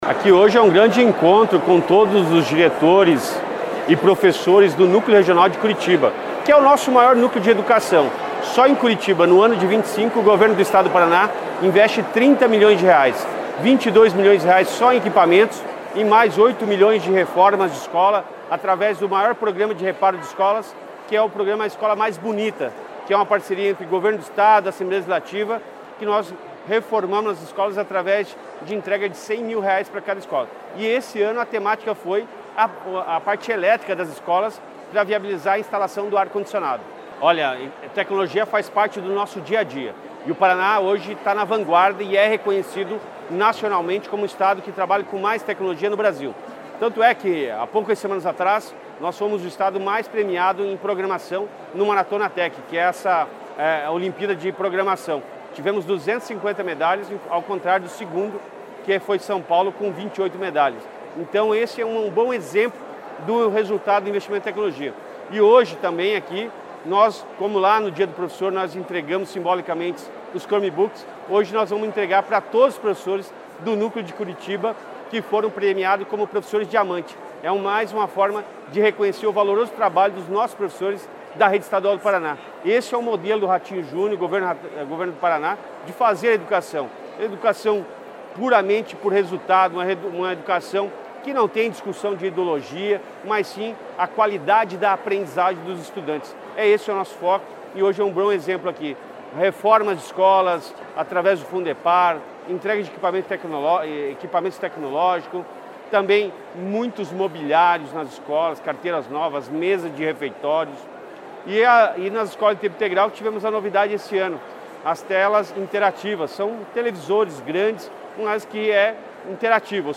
Sonora do secretário da Educação, Roni Miranda, sobre a entrega de equipamentos e melhorias para escolas estaduais em Curitiba